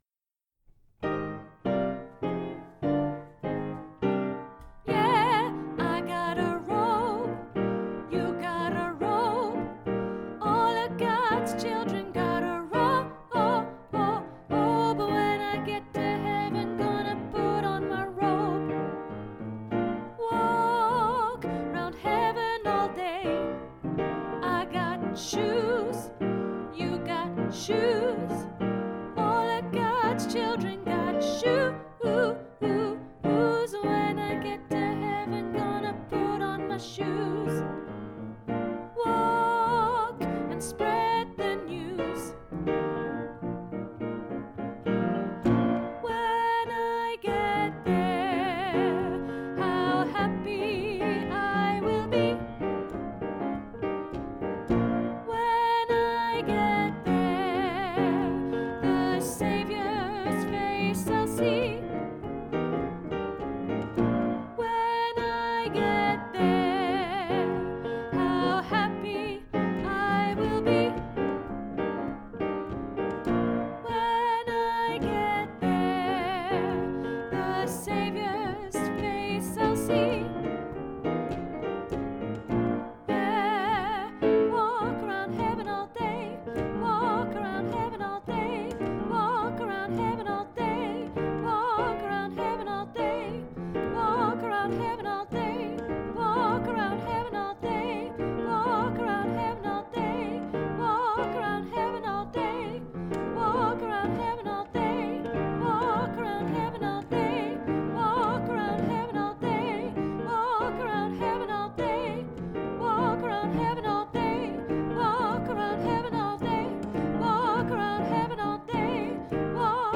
I got a robe Alto - Three Valleys Gospel Choir